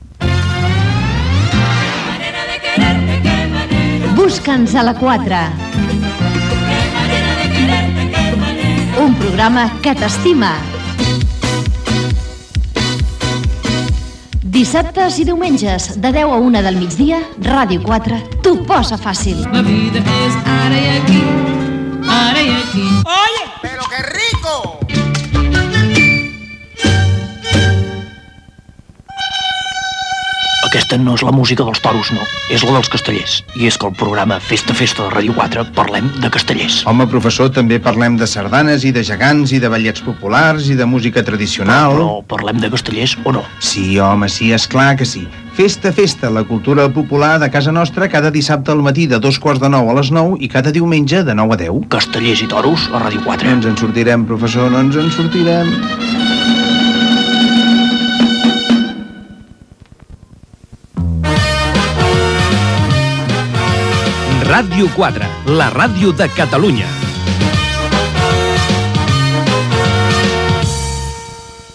cb7e15a1a01feeed1b56c1f72903c3f97dd39aaf.mp3 Títol Ràdio 4 Emissora Ràdio 4 Cadena RNE Titularitat Pública estatal Descripció Promos dels programes "Busca'ns a la 4" i "Fes ta festa", indicatiu de l'emissora.